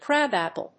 アクセントcráb àpple